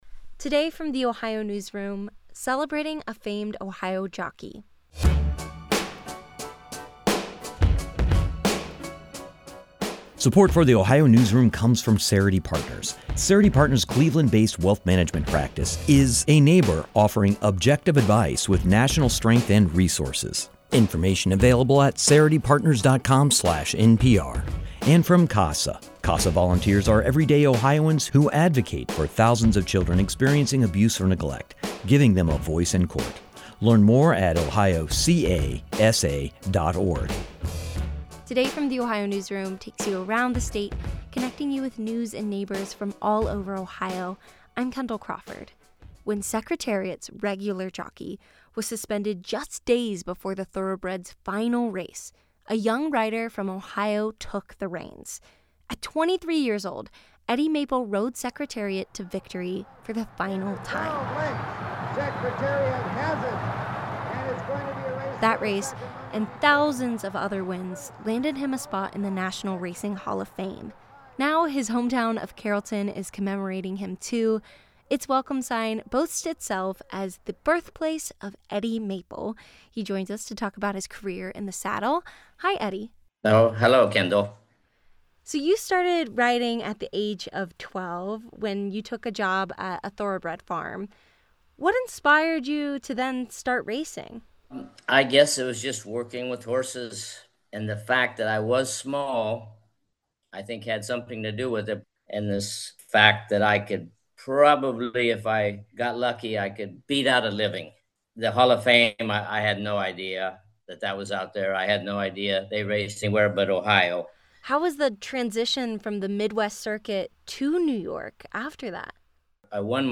Eddie Maple sat down the The Ohio Newsroom to reflect on his illustrious career in the saddle.
This interview has been edited lightly for brevity and clarity.